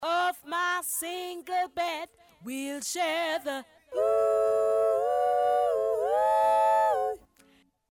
chœur.mp3